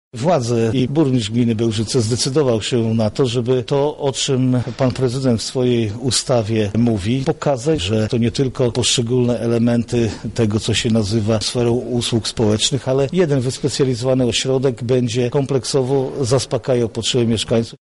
-mówi Marszałek Województwa Lubelskiego Jarosław Stawiarski.